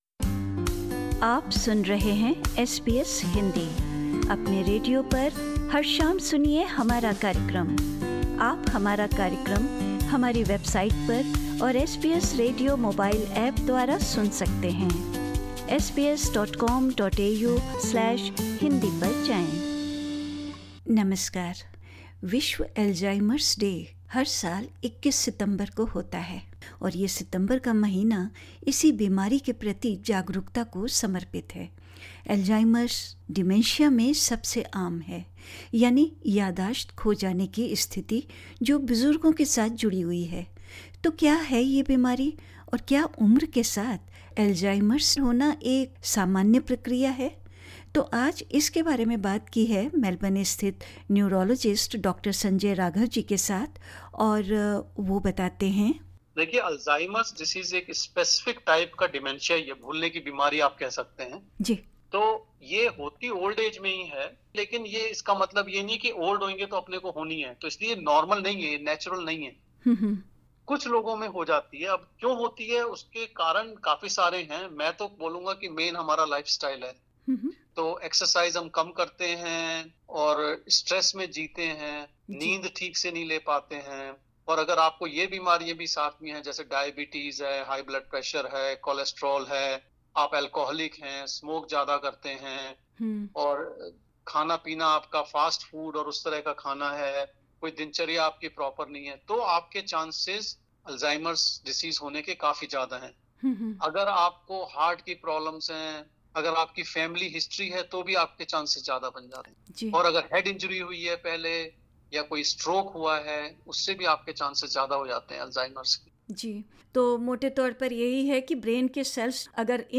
Understanding the disease and how to care for people with dementia SBS Hindi 10:45 Hindi Note: We would like to inform you that the information expressed in this interview is of general nature.